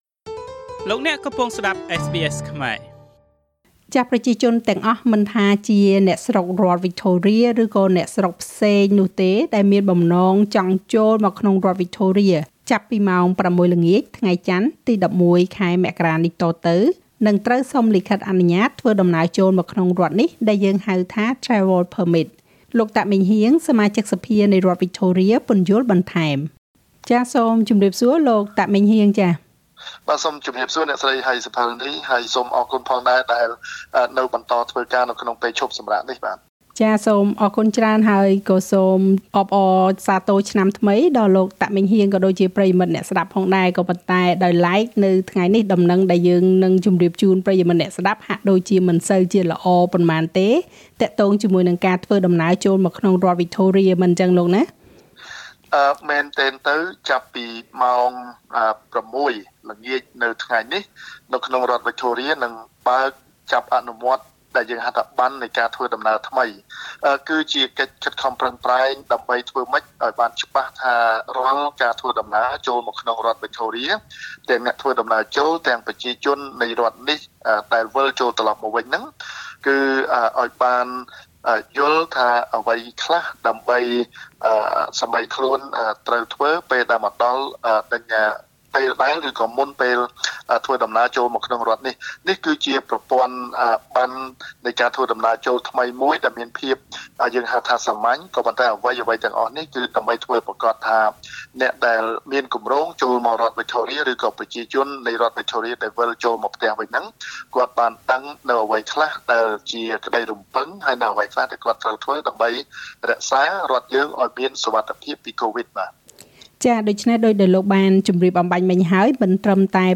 ប្រជាជនទាំងអស់មិនថាជាអ្នកស្រុកវិចថូរៀ ឬអ្នកស្រុកផ្សេងនោះទេ ដែលមានបំណងចូលមកក្នុងរដ្ឋវិចថូរៀ ចាប់ពីម៉ោង៦ល្ងាចថ្ងៃចន្ទ ទី១១ ខែមករា តទៅ នឹងត្រូវសុំលិខិតអនុញ្ញាតិធ្វើដំណើរចូលមកក្នុងរដ្ឋនេះ។ លោក តាក ម៉េងហ៊ាង សមាជិកសភានៃរដ្ឋវិចថូរៀ ពន្យល់បន្ថែម។